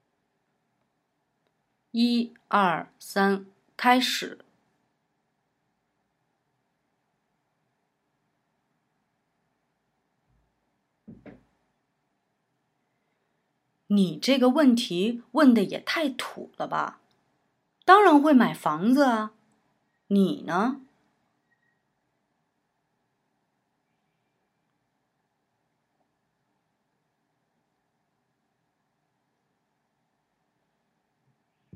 Höre dir Audio 3 an, in dem nur Part B eingesprochen wurde, und übernimm diesmal Part A! Damit du weißt, wann du einsetzen musst, gibt es vorab ein Startsignal.
Übung 3: Sprich Part A!